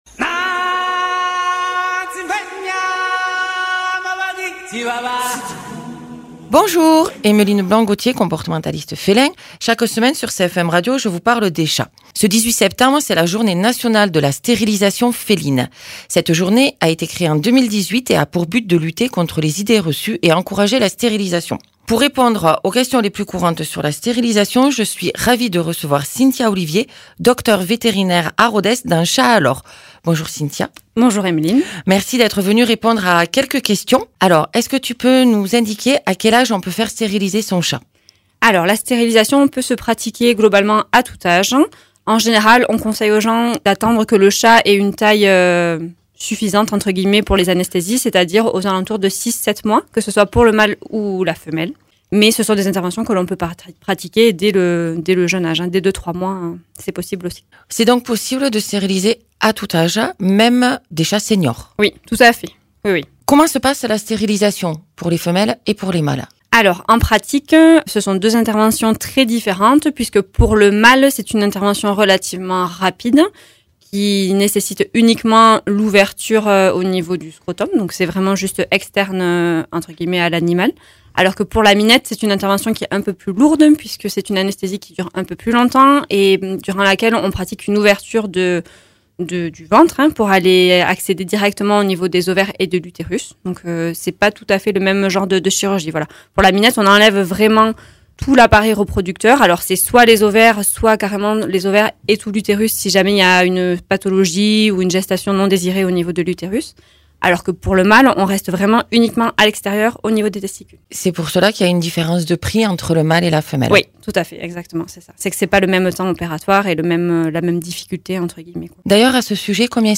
Docteur vétérinaire